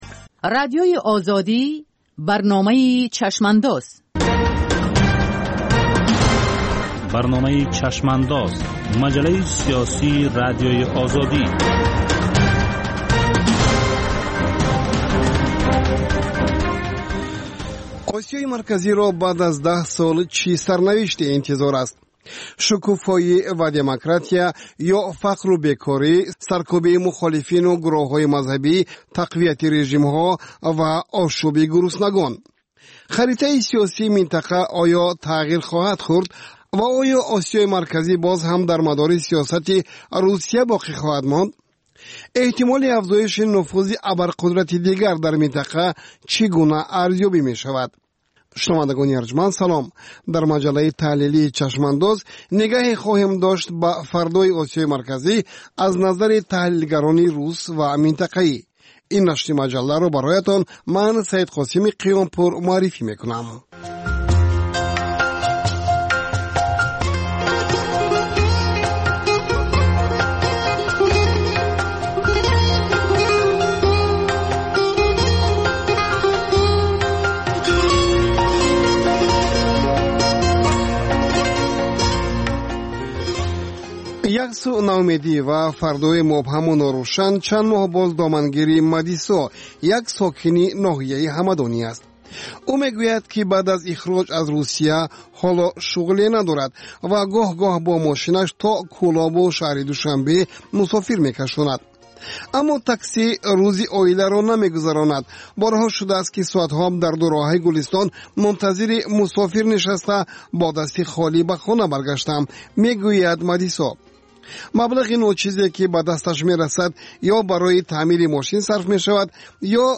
Баррасӣ ва таҳлили муҳимтарин рӯйдодҳои сиёсии рӯз дар маҷаллаи "Чашмандоз". Гуфтугӯ бо коршиносон, масъулини давлатӣ, намояндагони созмонҳои байналмилалӣ.